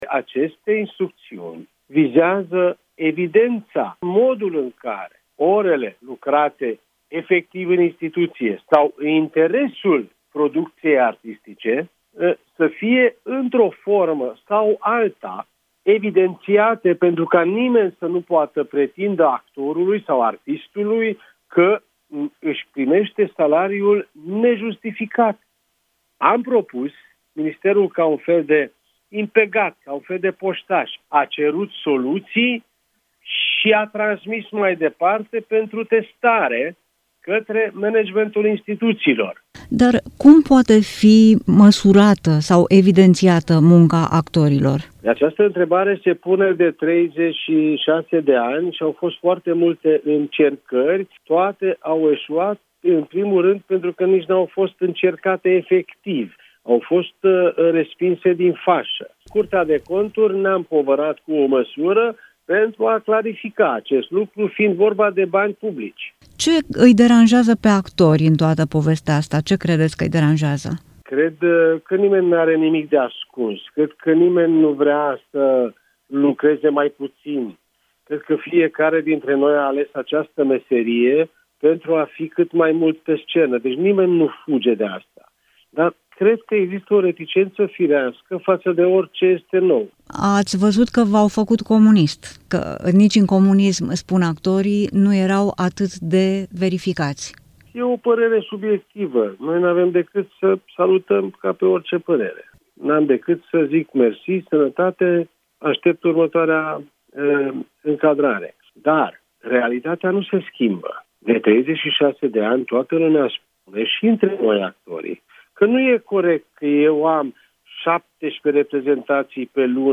Ministrul Culturii spune la Europa FM că reglementările nu sunt bătute în cuie – ele se pot schimba dacă se dovedește că aplicarea lor mai mult încurcă.